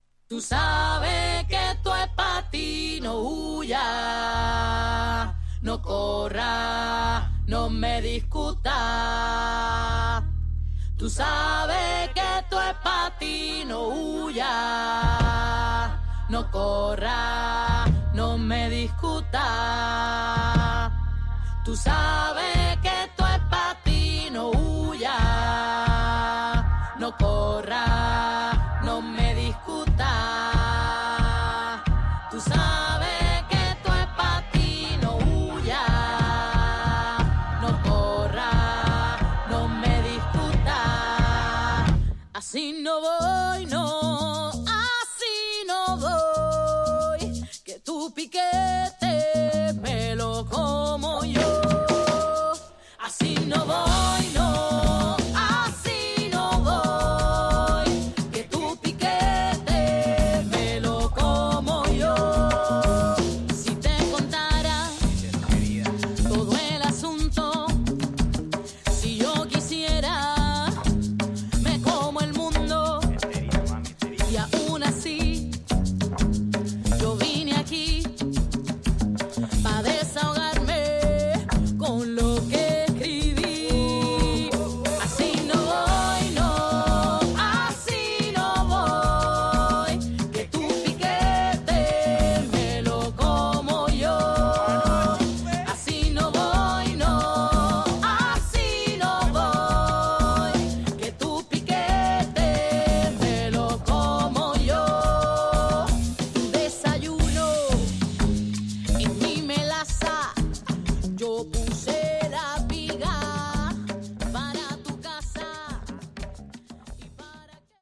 Tags: Tropical , Spain